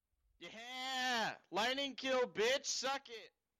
Tank Kill